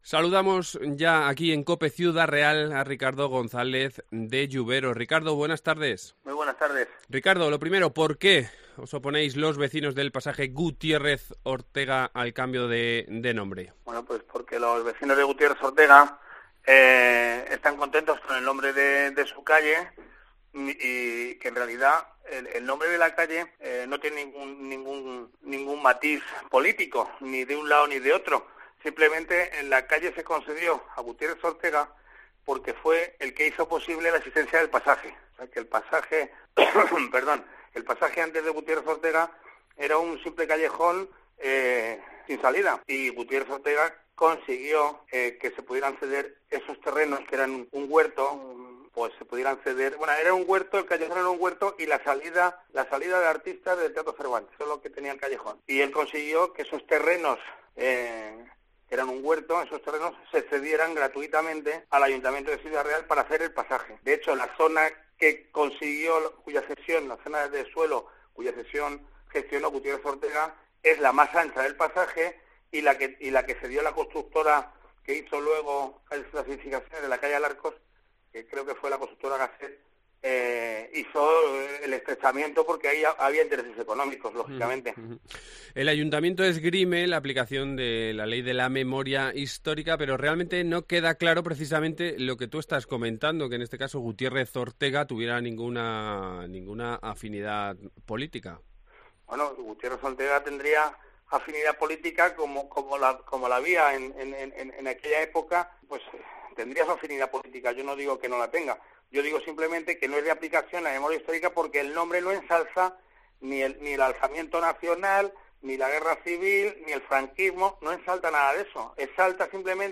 Hablamos con uno de los vecinos afectados